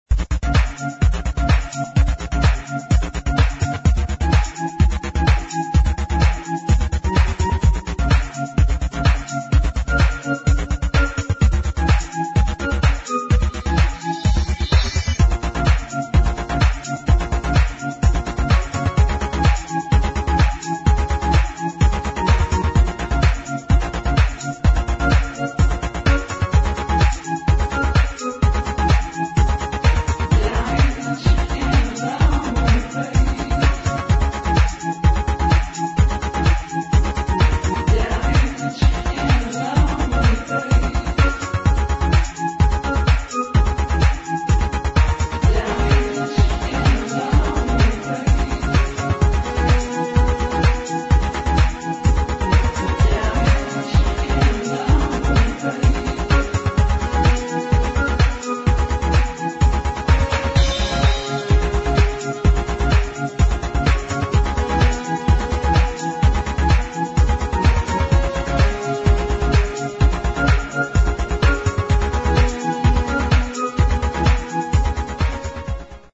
[ TECH HOUSE | DUB DISCO ]